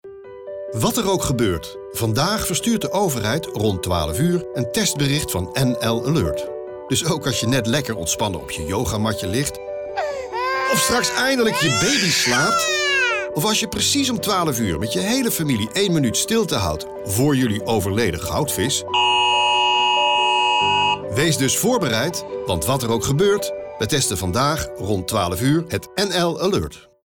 Radiospot testbericht NL-Alert 1 december - aankondiging